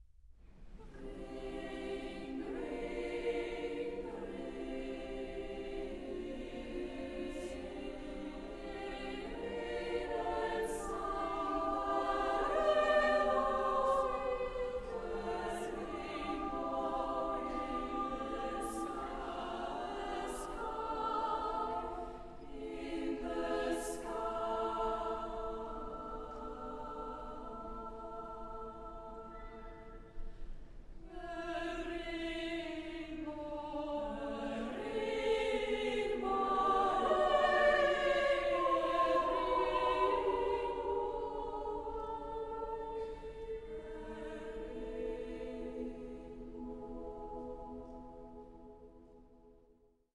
SSAA OR TTBB (4 voices men OR women) ; Full score.
Consultable under : 20ème Profane Acappella
Secular ; Poem ; Contemporary